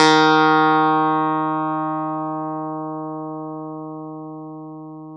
CLV_ClavDBD#4 2c.wav